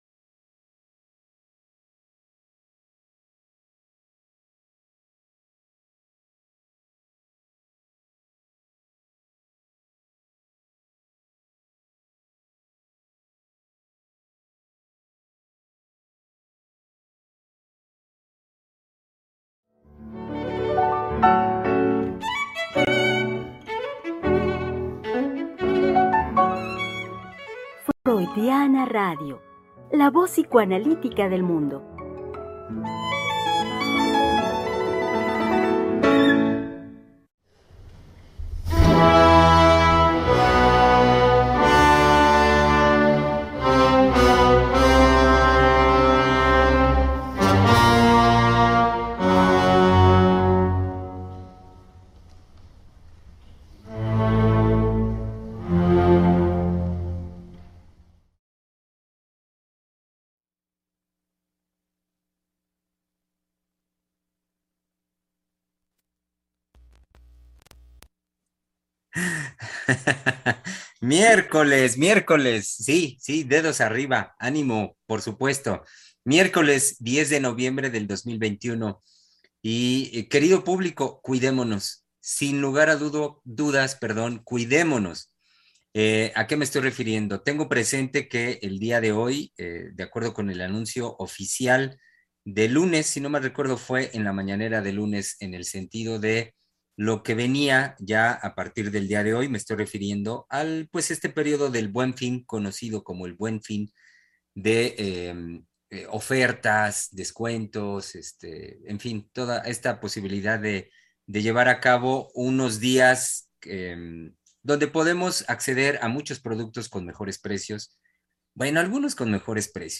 ¡No se lo pierdan, conversemos en tiempos de pandemia!